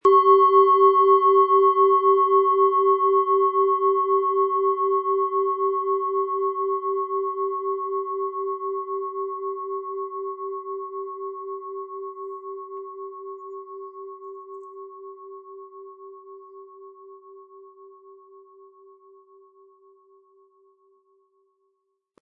Tageston
Planetenschale® Gleiche Kopflastige Energie aus & Gestärkt werden mit Tageston, Ø 11,3 cm inkl. Klöppel
Im Sound-Player - Jetzt reinhören hören Sie den Original-Ton dieser Schale. Wir haben versucht den Ton so authentisch wie machbar hörbar zu machen, damit Sie hören können, wie die Klangschale bei Ihnen klingen wird.
Lieferung mit richtigem Schlägel, er lässt die Planetenschale Tageston harmonisch und wohltuend schwingen.
MaterialBronze